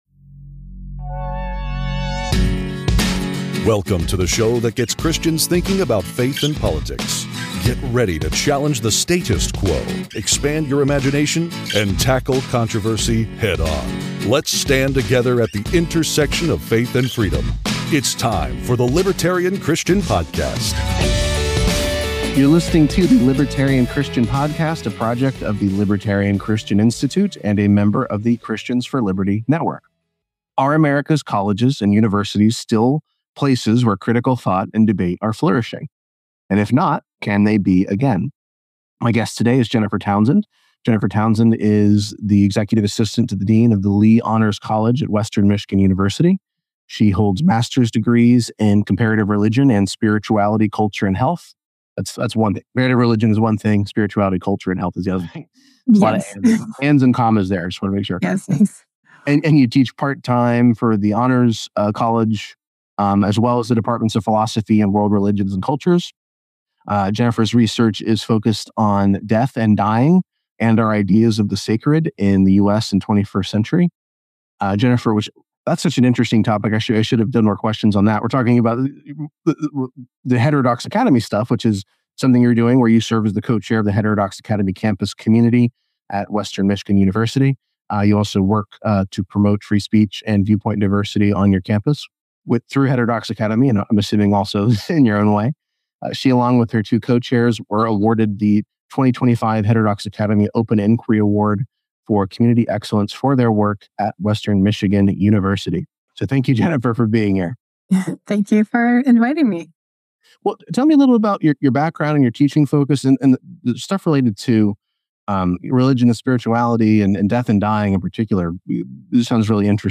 A central thread of this conversation is viewpoint diversity versus identity-based diversity.